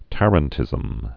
(tărən-tĭzəm)